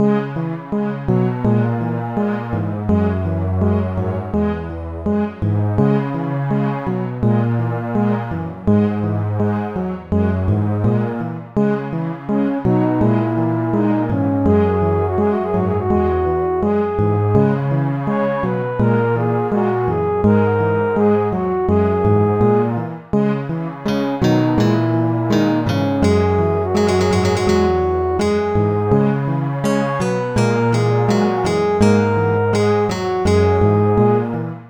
- попытался в олдскул))